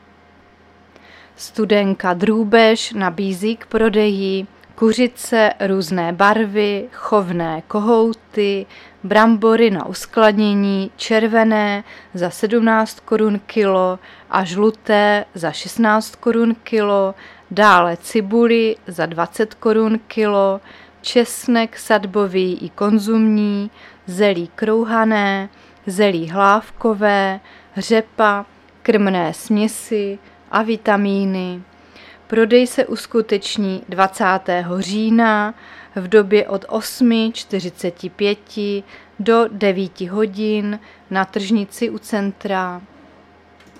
Záznam hlášení místního rozhlasu 18.10.2023
Zařazení: Rozhlas